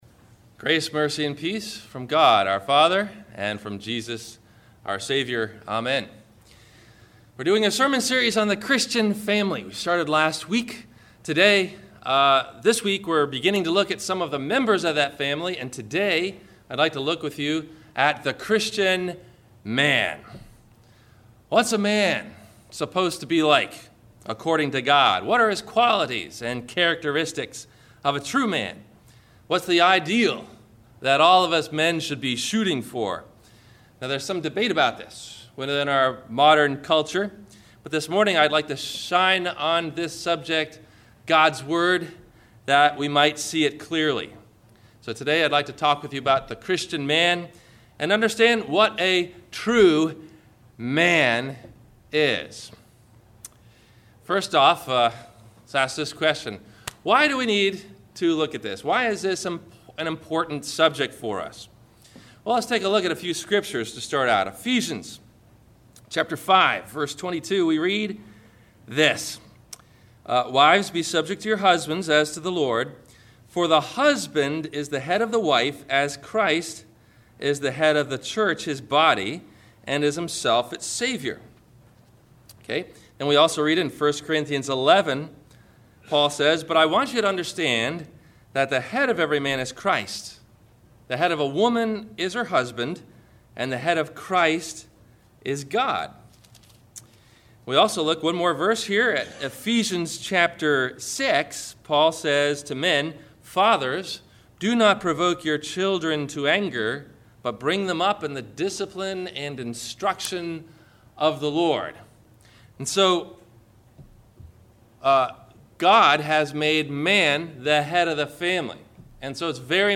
A Healthy Body – Sermon – November 13 2011